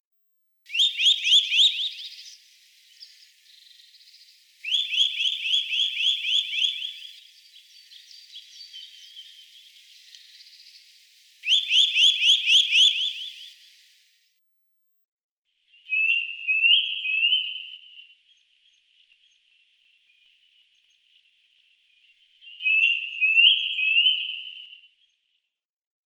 Подборка включает разные варианты голосов, записанных в естественной среде обитания.
Позывки пернатых